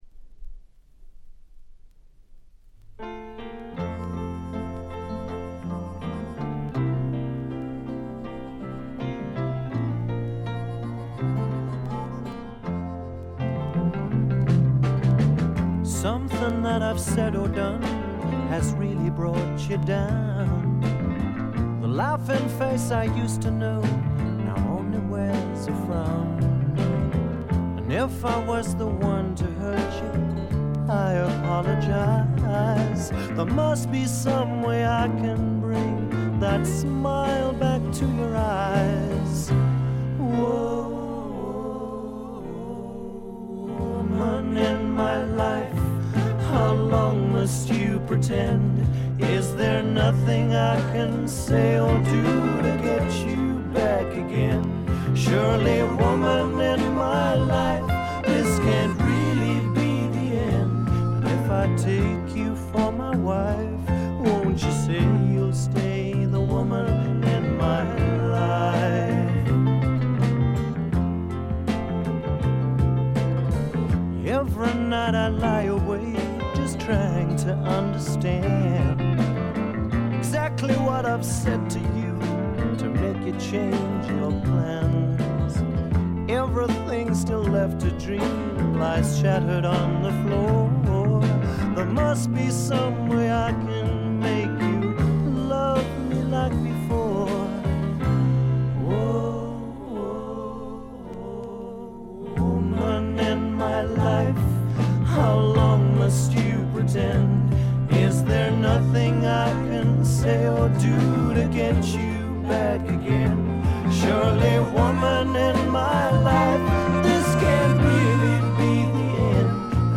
静音部でのバックグラウンドノイズ、チリプチ少し。
試聴曲は現品からの取り込み音源です。
Vocals, Piano